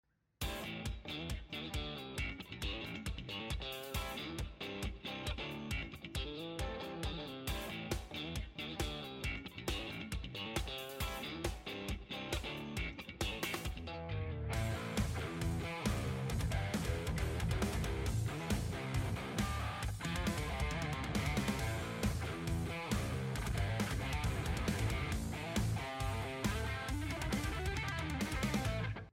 Need More 7 String Slap Sound Effects Free Download